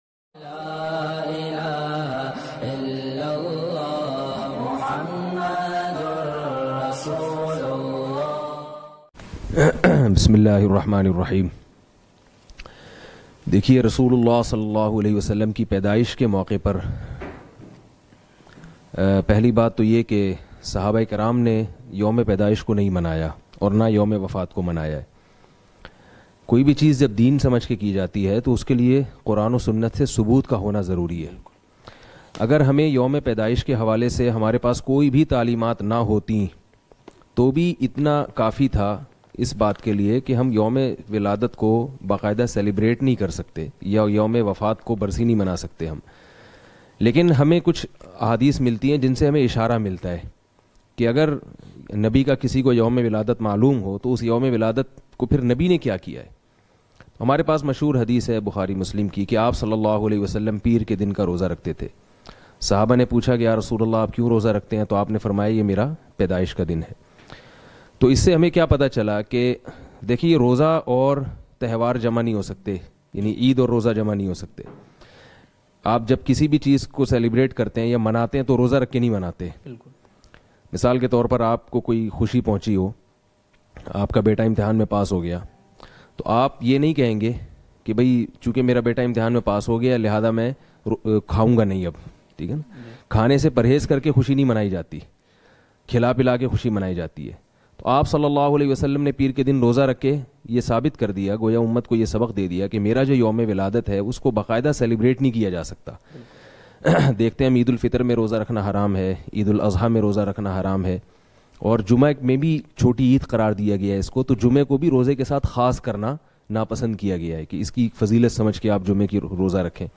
12 Rabi Ul Awwal Ki Haqeeqat bayan mp3
12 Rabi Ul Awwal Ki Haqeeqat Mufti Tariq Masood (Very Important Bayan).mp3